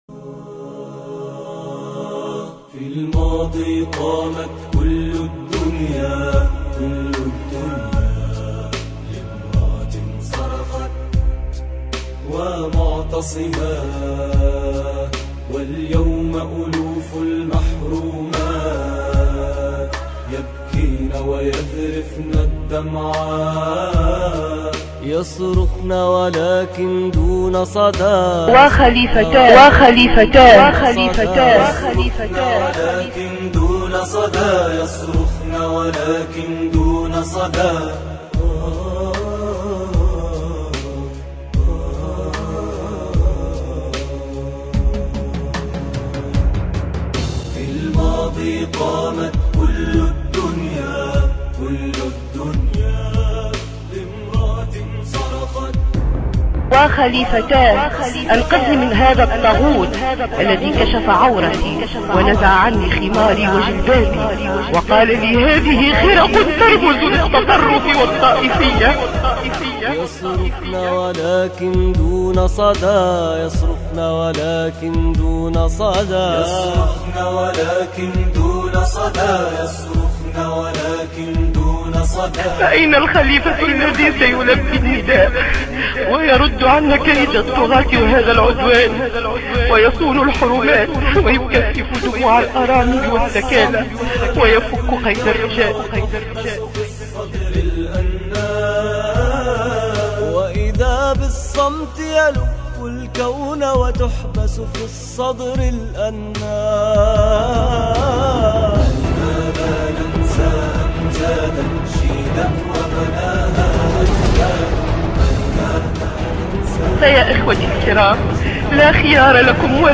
أنشودة